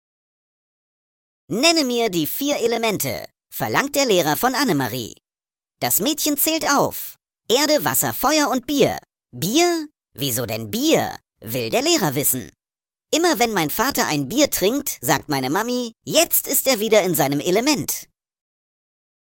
Vorgetragen von unseren attraktiven SchauspielerInnen.